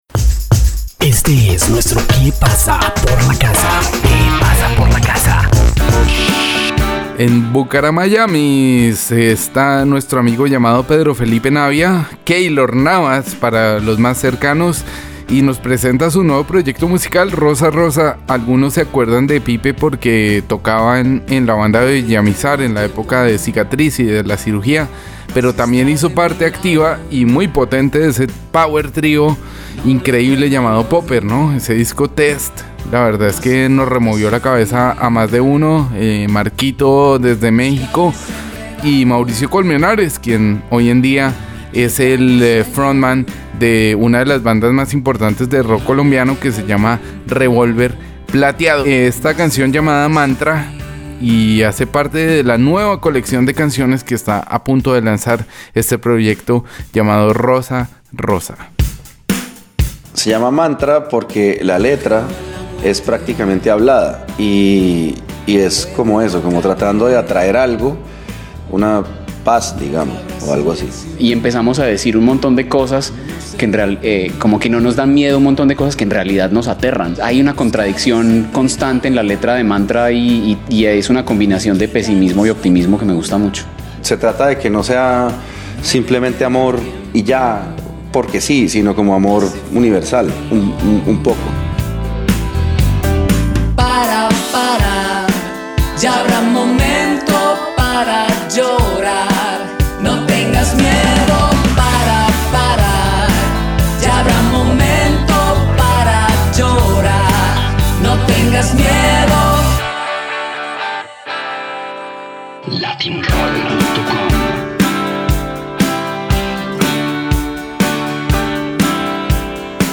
batería
teclados
guitarra acústica